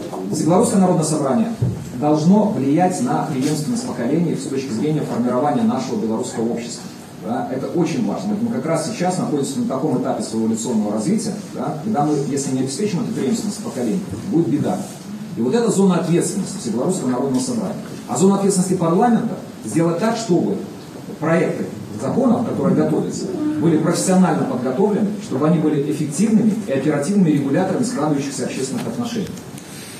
Масштабное мероприятие под девизом «Единство. Истина. Будущее» с участием делегации нашего города, Барановичского, Ляховичского, Ивацевичского, Ганцевичского районов состоялось на базе Барановичского государственного университета.
Свое мнение по важнейшим вопросам высказали эксперты – председатель Постоянной комиссии Совета Республики Национального собрания по законодательству и государственному строительству Сергей Сивец.